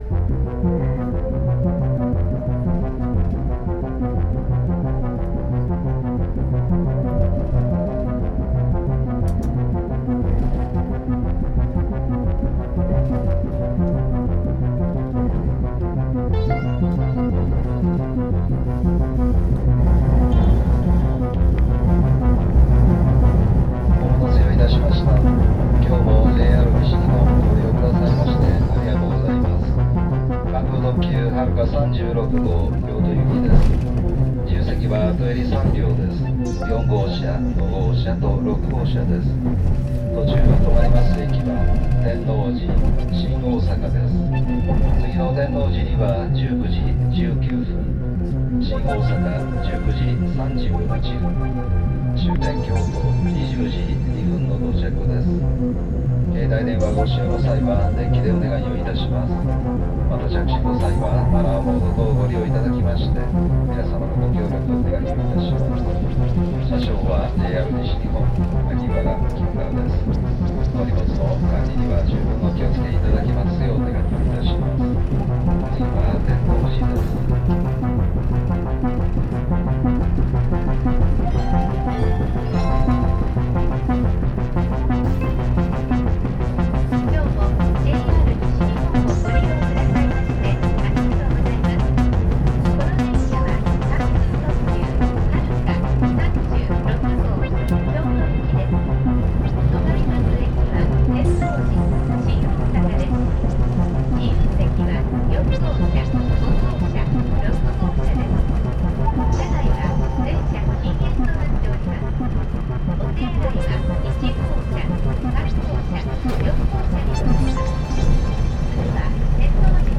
Chem - aka, train to hell and back. Experimental. 31/3/23 (